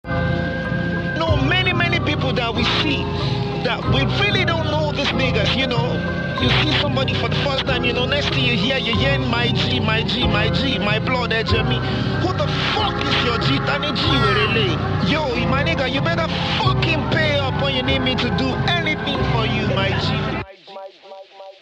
Nigeria Music 2025 0:21